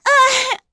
Hanus-Vox_Damage_03.wav